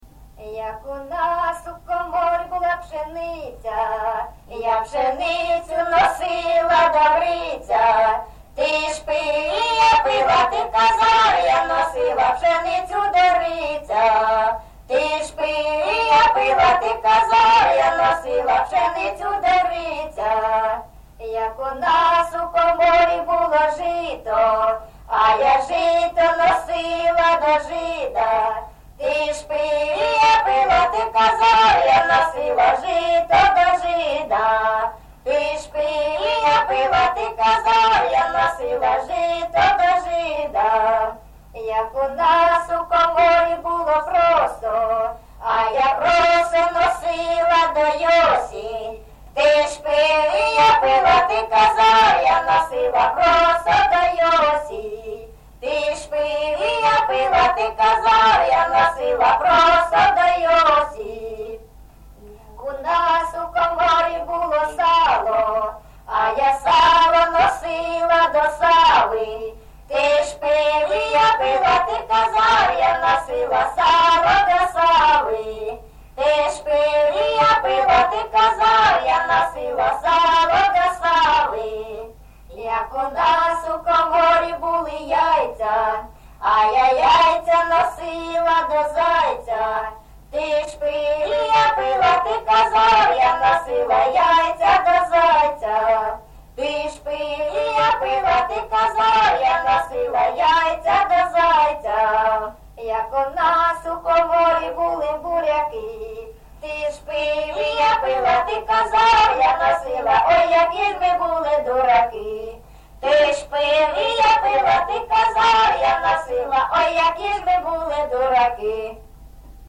ЖанрЖартівливі, Пʼяницькі
Місце записус-ще Троїцьке, Сватівський район, Луганська обл., Україна, Слобожанщина